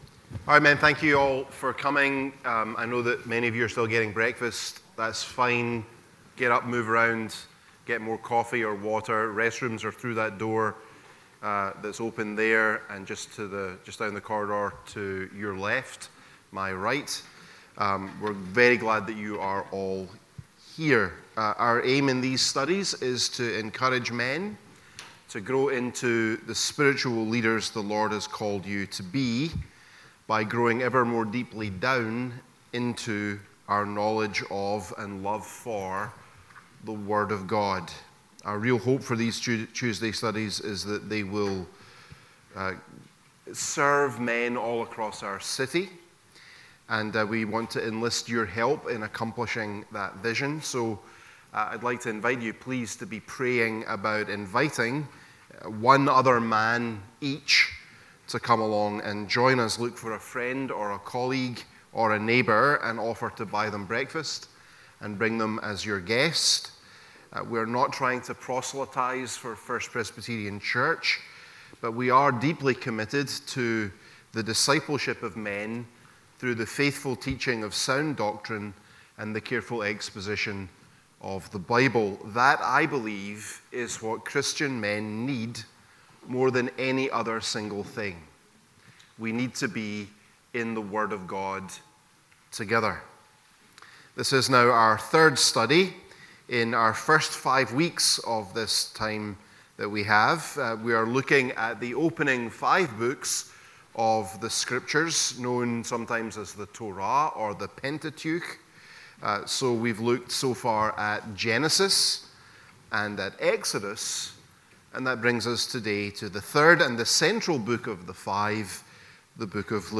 Tuesday Men's Breakfast Series